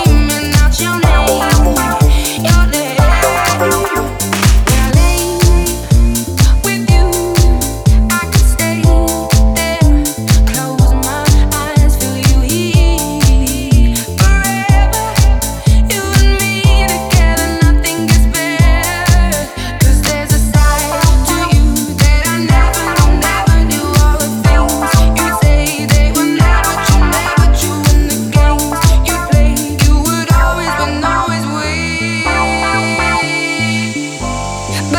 Жанр: Танцевальные / Хаус
# House